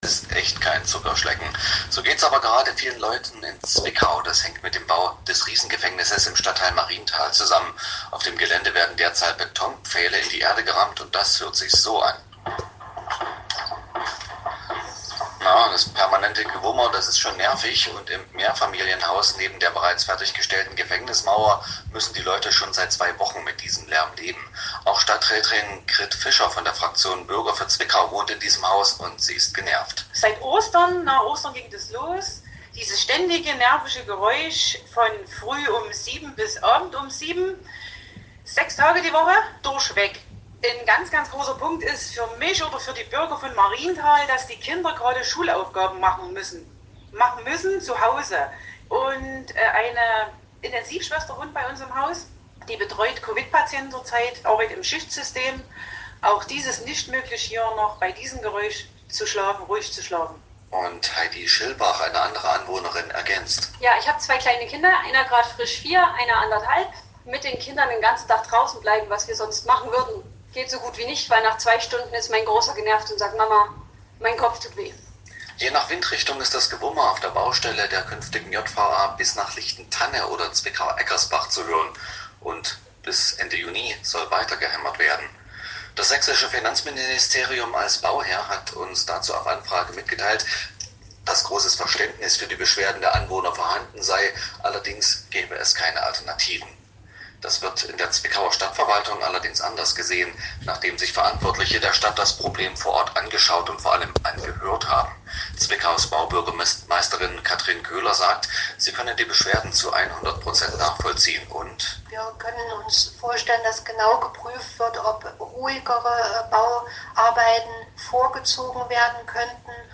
Radiobeitrag zur JVA-Baustelle
Interview von MDR Sachsen mit Grit Fischer:
Die Stadträtin Grit Fischer wurde heute vom Sender MDR Sachsen zu der seit Wochen andauernden JVA-Baustelle interviewt.